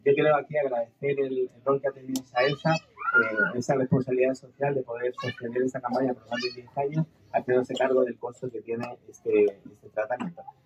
La actividad se realizó en el jardín infantil “Raíces de mi pueblo” en la comuna de Paillaco.
Audio-seremi-Medio-Ambiente-Los-Rios-Alberto-Tacon.mp3